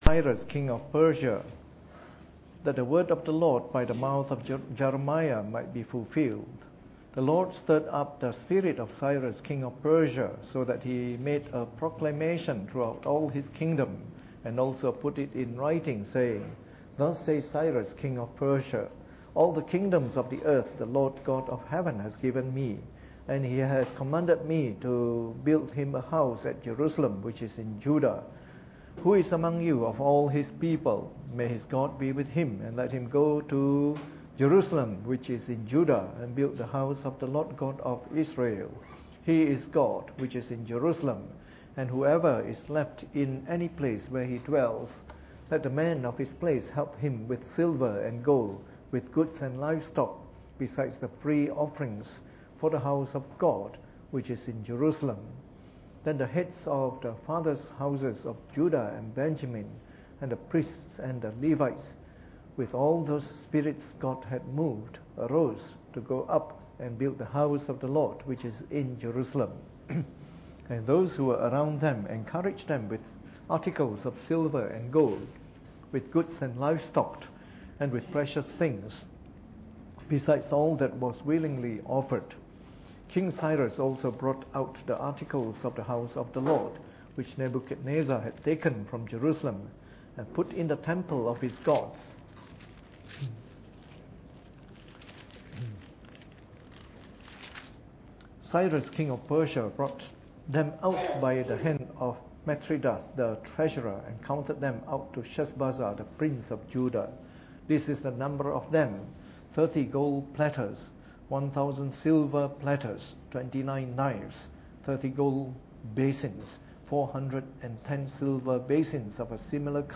Preached on the 8th of January 2014 during the Bible Study, from our new series of talks on the Book of Ezra.